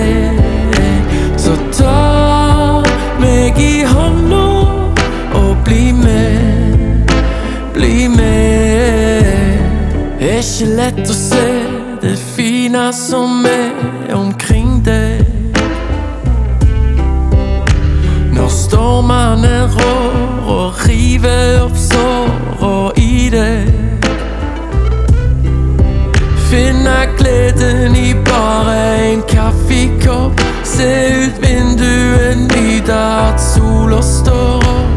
Traditional Pop, Vocal